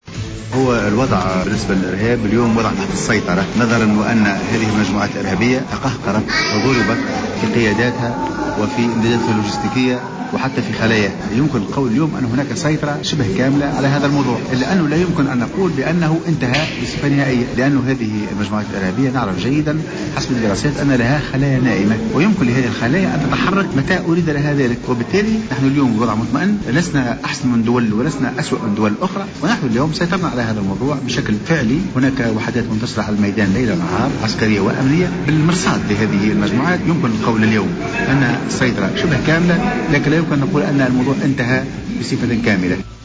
أكد الناطق الرسمي السابق باسم وزارة الدفاع الوطني العميد المتقاعد مختار بن نصر ,في تصريح لجوهرة أف أم لدى إشرافه اليوم على ندوة نظمتها جمعية قدماء ضباط الجيش, أن الوضع في الشعانبي تحت السيطرة محذرا في الآن ذاته من الخلايا النائمة للجماعات الإرهابية والتي يمكن لها في أي وقت أن تتحرك في أي وقت وبالتالي لا يمكن اعتبار المسألة محسومة تماما.